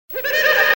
Play, download and share Casseta original sound button!!!!
risada-do-casseta.mp3